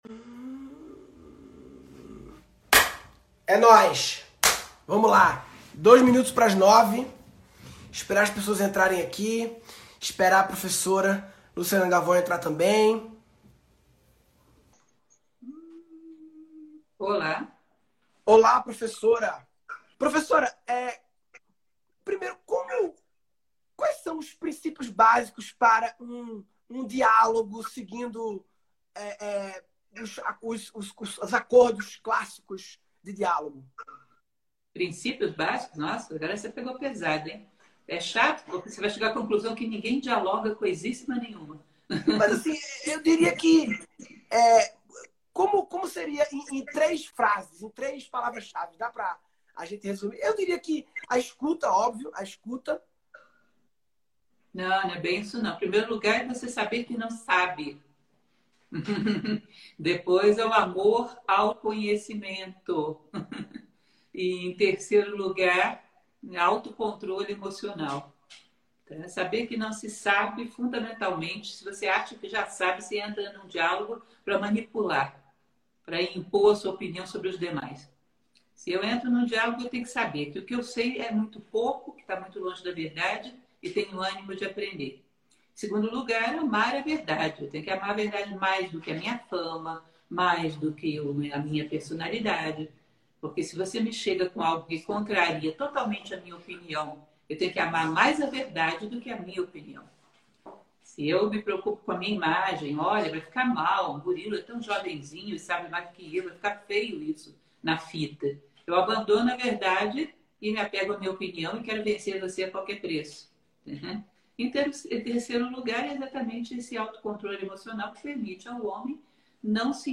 #142 - Murilo Gun entrevista Lúcia Helena Galvão - live no instagram